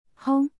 (hōng) — boom